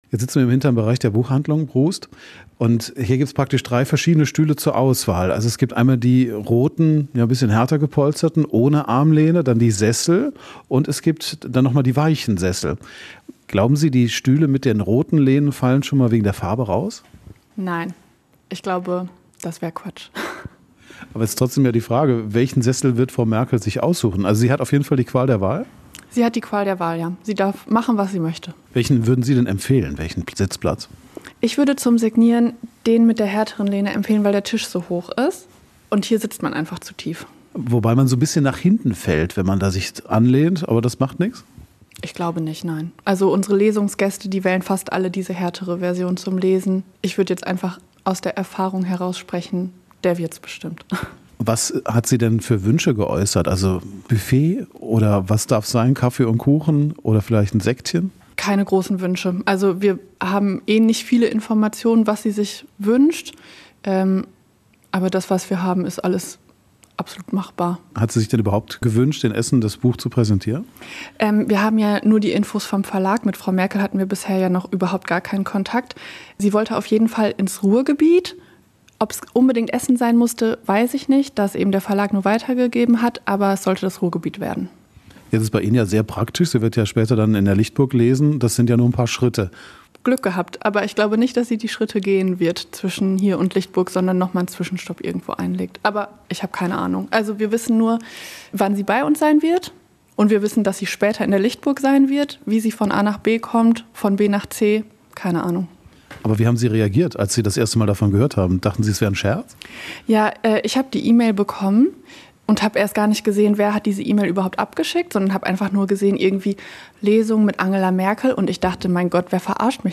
Nach dem Interview will er noch wissen, wen sie sich für die nächste Signierstunde wünschen würden?